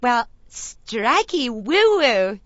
gutterball-3/Gutterball 3/Commentators/Poogie/strikey_woo_woo.wav at 893fa999aa1c669c5225bd02df370bcdee4d93ae
strikey_woo_woo.wav